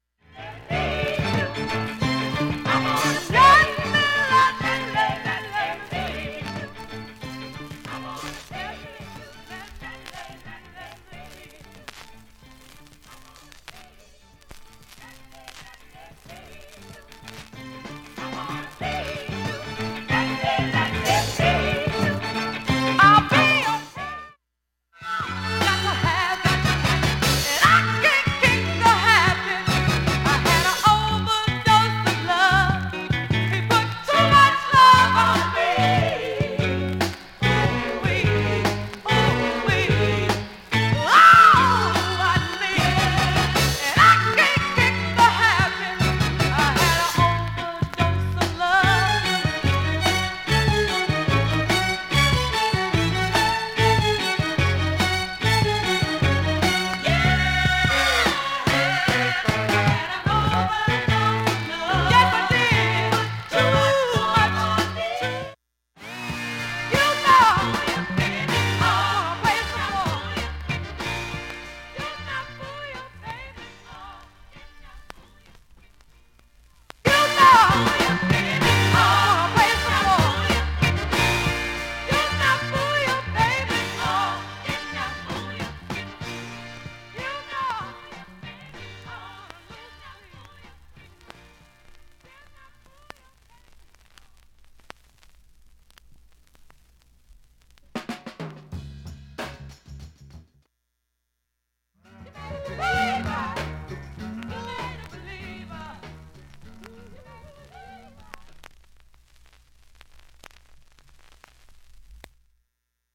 A面もチリ出ますがB面よりだいぶかすかで
かすかなプツが７回出ます
かすかなプツが５回と６回出ます(1m06s〜)
かすかなプツが4回出ます(1m42s〜)